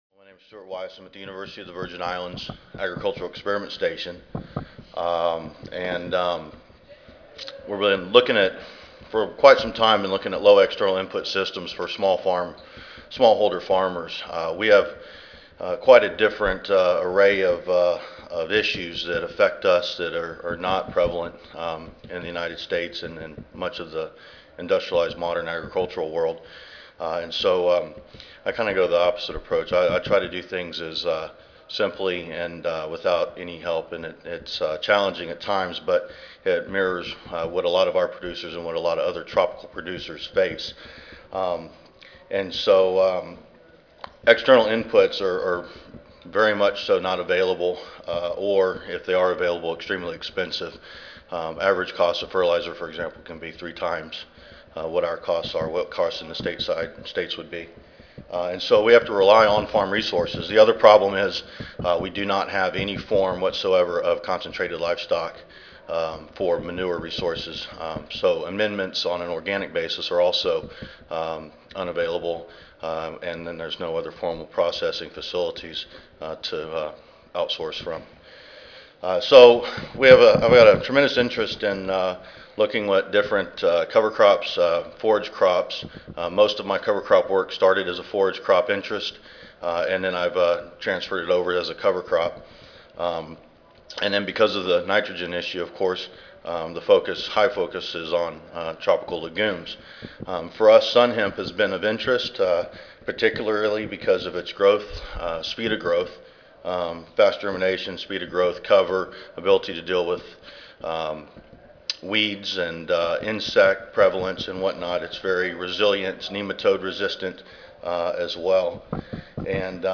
A08 Business Meeting (2010 Annual Meeting (Oct. 31 - Nov. 3, 2010))
University of the Virgin islands Audio File Recorded presentation